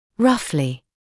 [‘rʌflɪ][‘рафли]примерно, ориентировочно; грубо